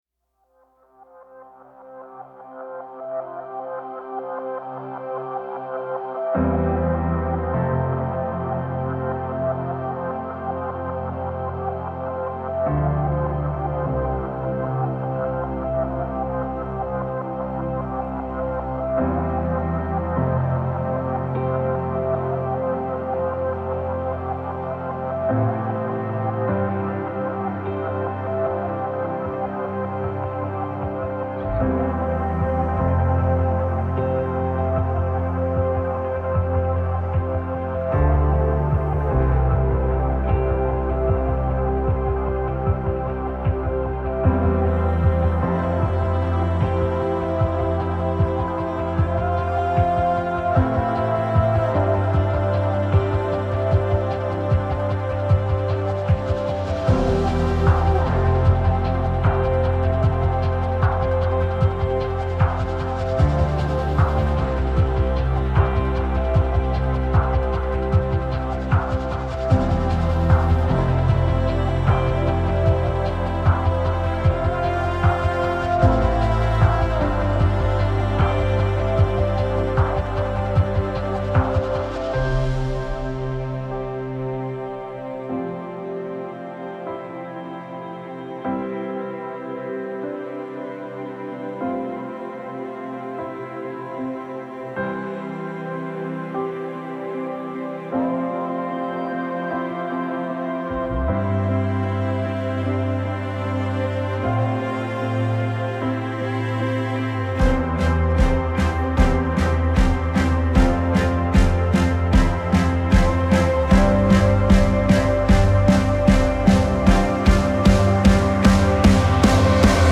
موسیقی بی کلام امید بخش موسیقی بی کلام نیو ایج